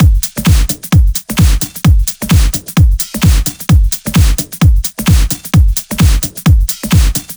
VFH2 130BPM Comboocha Kit 2.wav